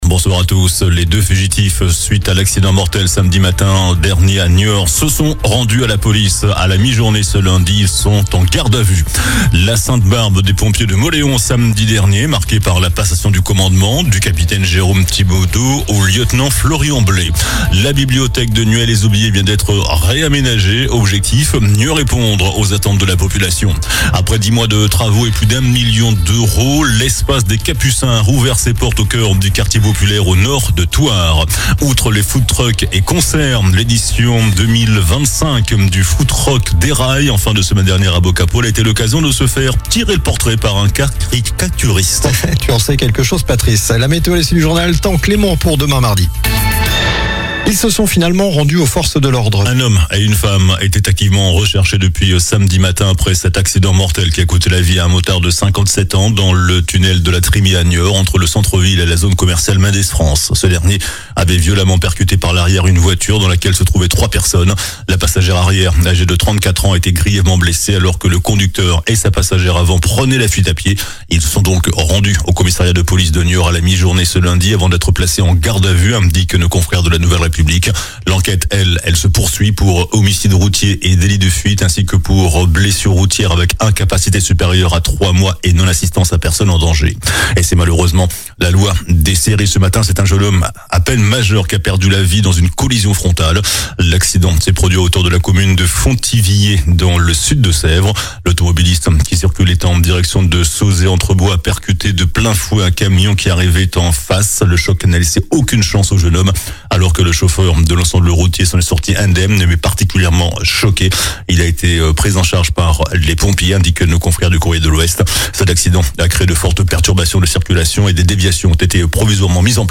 JOURNAL DU LUNDI 08 DECEMBRE ( SOIR)